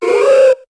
Cri de Hoothoot dans Pokémon HOME.